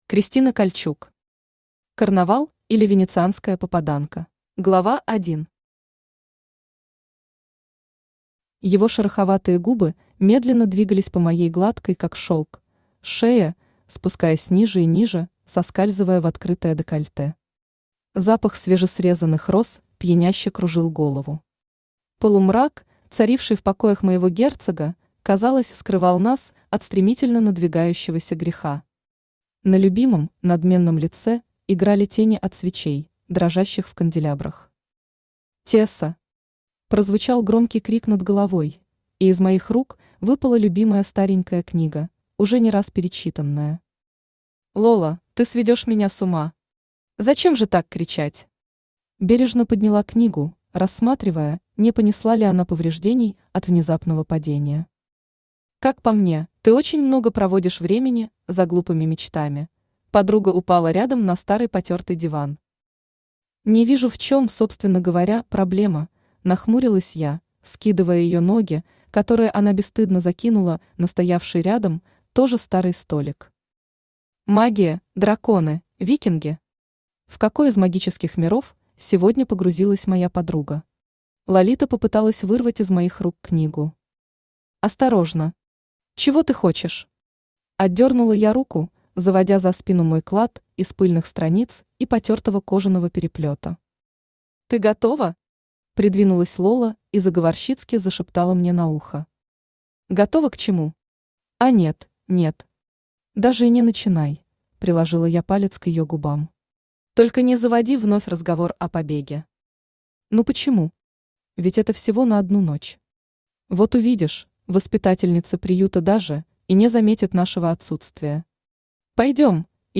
Аудиокнига Карнавал, или Венецианская попаданка | Библиотека аудиокниг
Aудиокнига Карнавал, или Венецианская попаданка Автор Кристина Кальчук Читает аудиокнигу Авточтец ЛитРес.